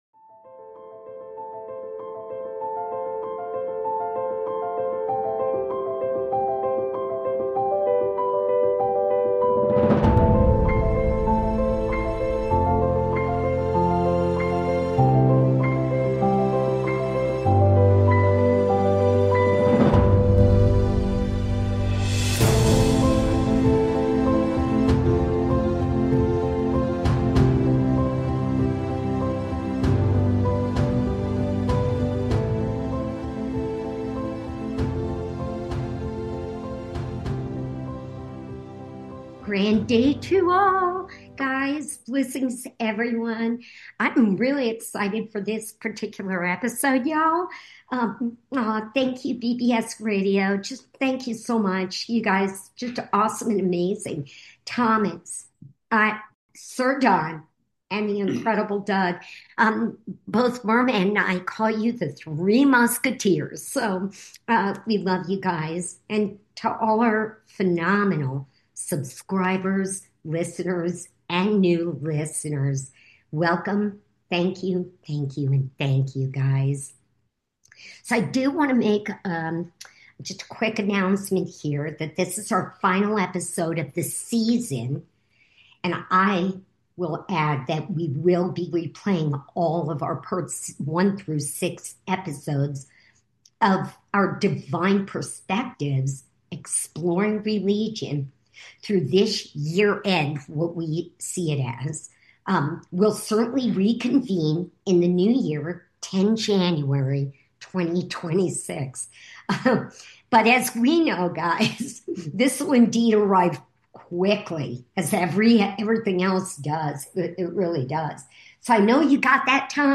Occasionally, we'll also take "call-ins" and conduct "one-on-one" interviews.